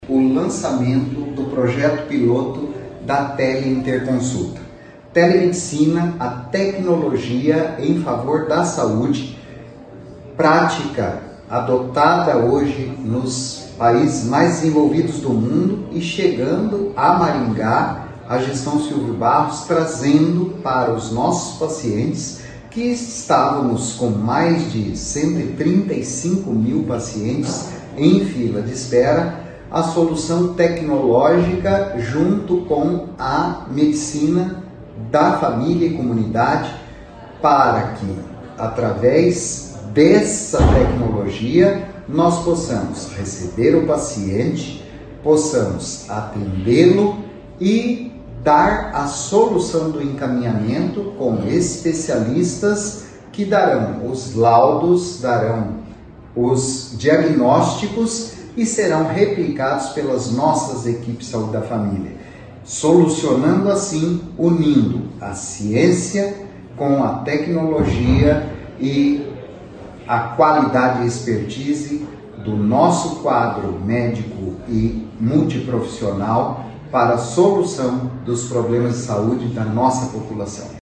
O secretário de Saúde Antônio Carlos Nardi fala sobre o lançamento do projeto.